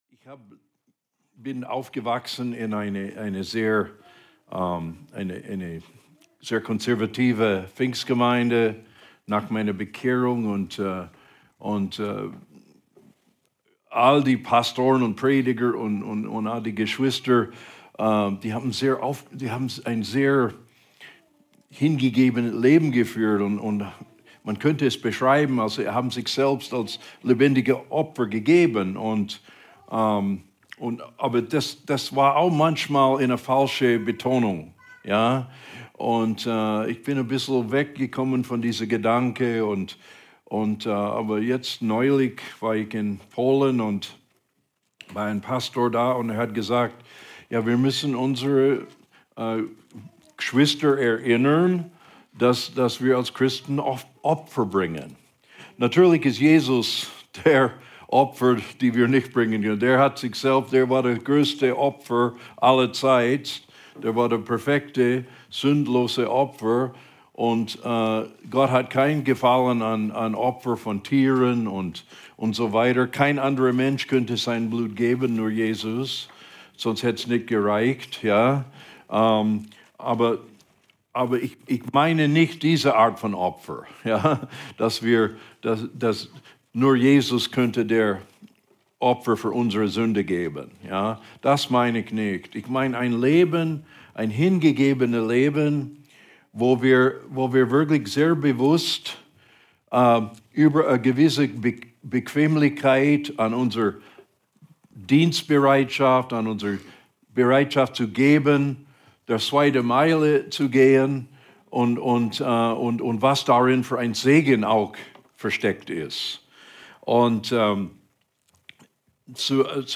Alle Predigten aus den Sonntagsgottesdiensten